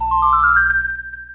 exclamation.mp3